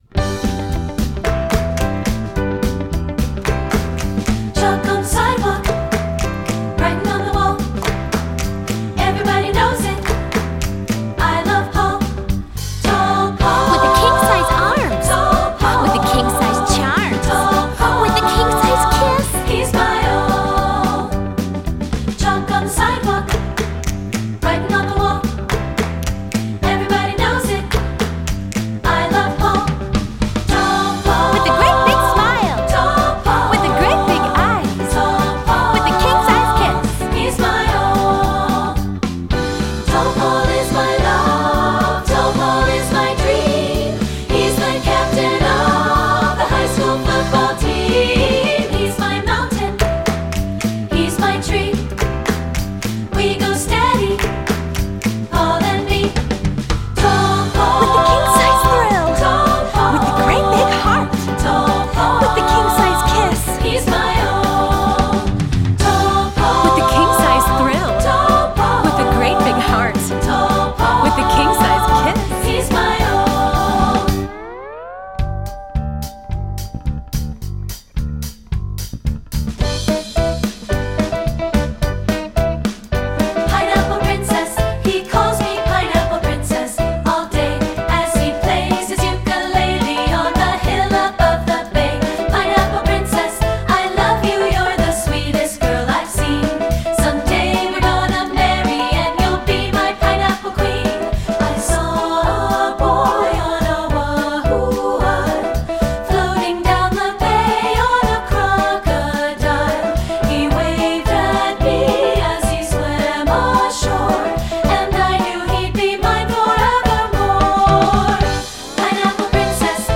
choral